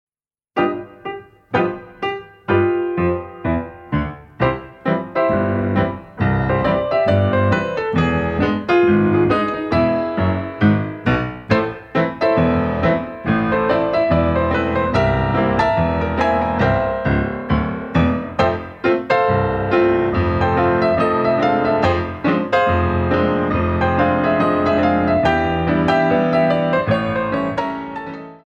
Grands battements